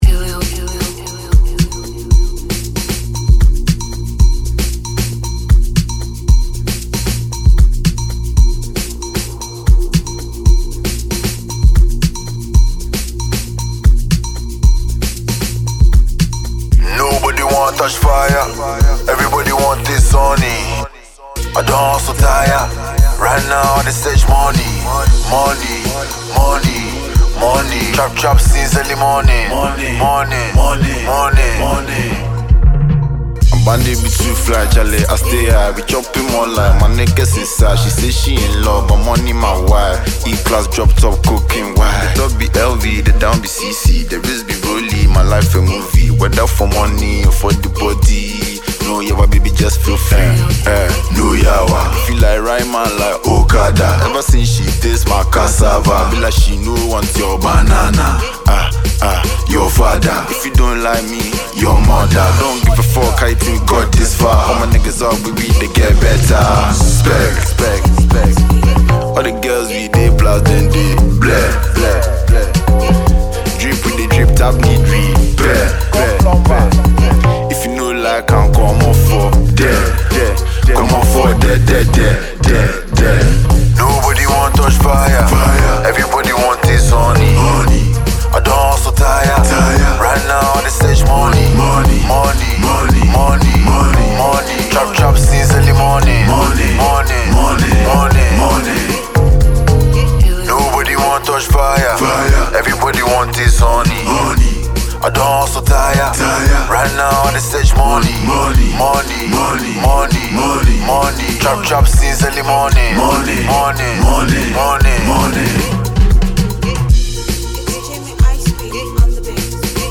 Afrobeat/Amapiano